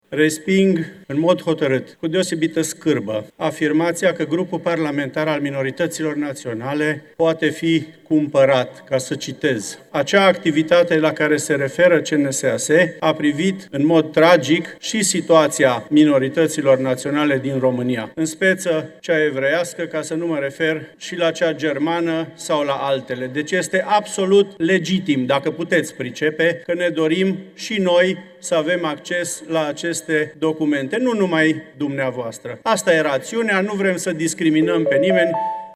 Deputatul Ovidiu Ganț, din partea Forumului German, membru al grupului minorităților naționale, inițiator al proiectului: „Este absolut legitim, dacă puteți pricepe, că ne dorim și noi să avem acces la aceste documente”